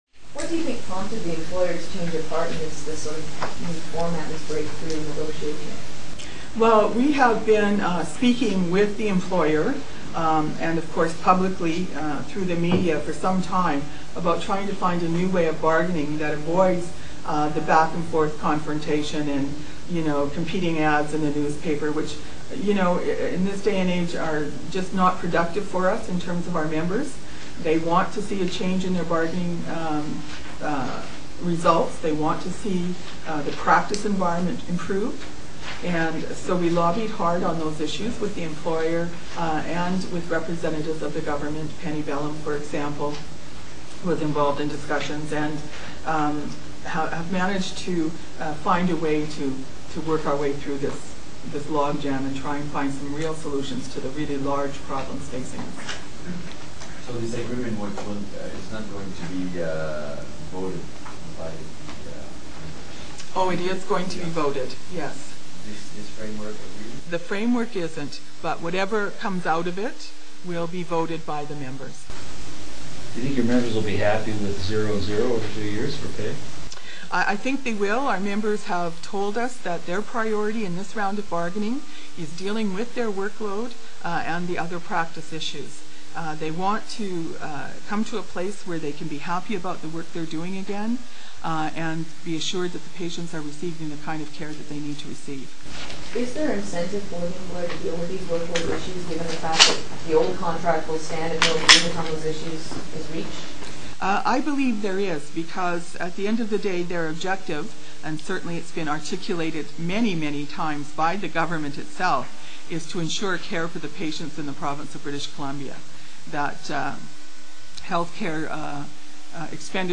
Webcast of the May 26, 2004 announcement that the Nurses� Bargaining Association has reached a Framework Agreement with the Health Employers� Association of British Columbia.